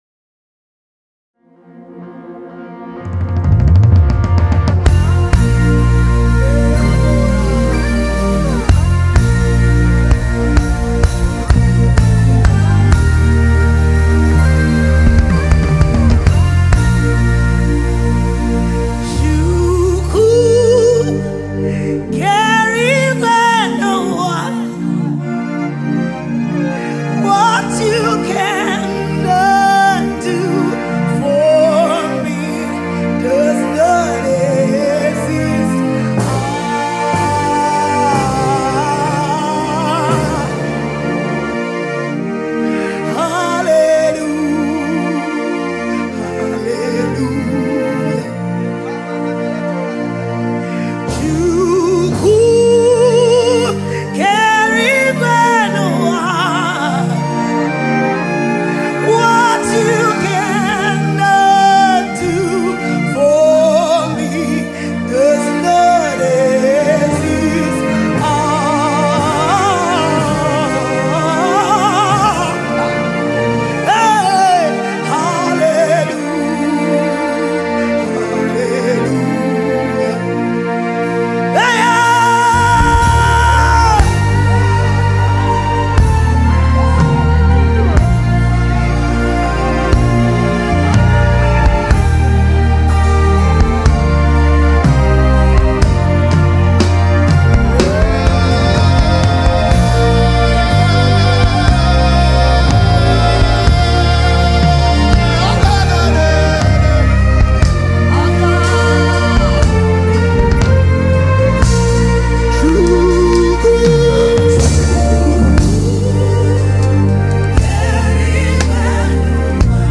Top Christian Songs